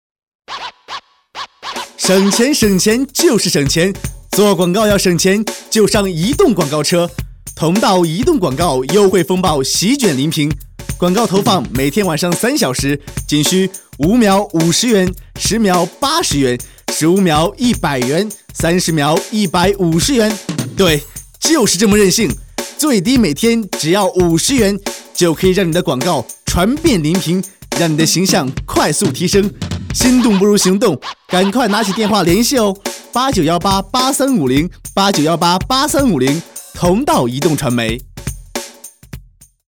Locutor masculino chino, grabación de spot publicitarios
A continuación un locutor masculino chino, realizando una recomendación publicitaria de una promoción.
Locutor-varón-chino-30.mp3